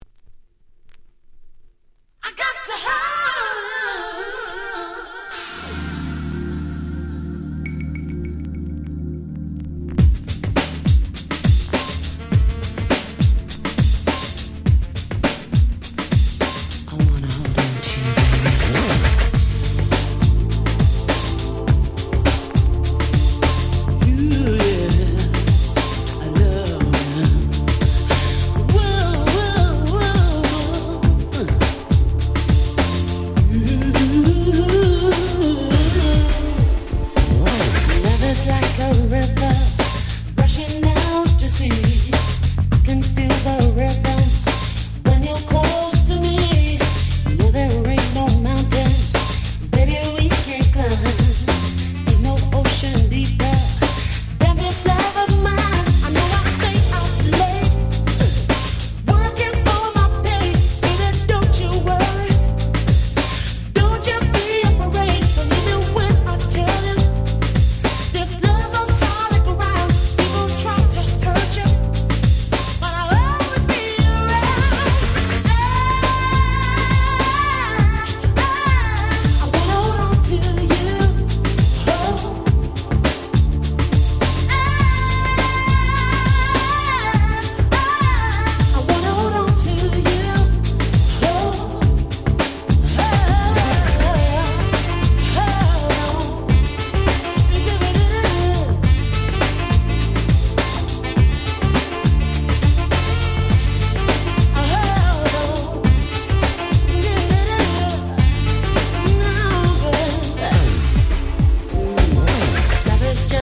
90's UK Soul UK R&B